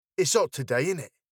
数あるアクセントの中で特に有名なものが、ロンドンの下町言葉であるコックニーです。
▼こんな発音です。
Its-hot-today-innit-.mp3